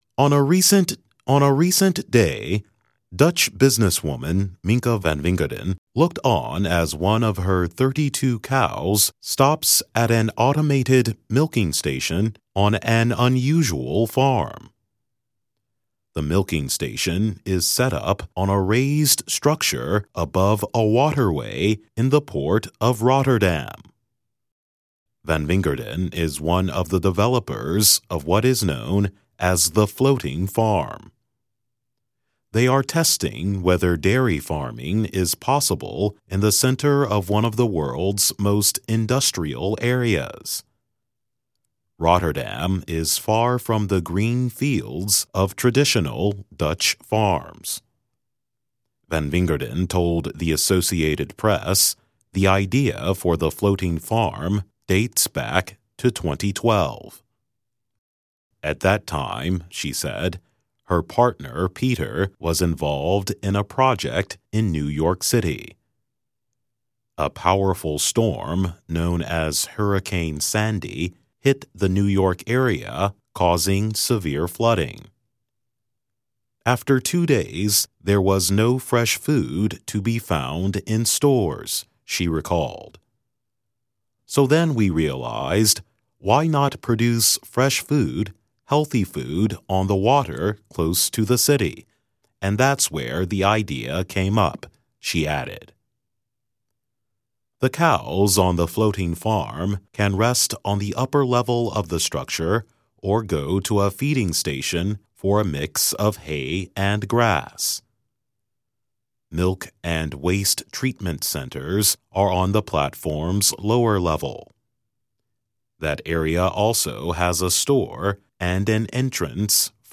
慢速英语:奶牛在鹿特丹尝试“漂浮农场”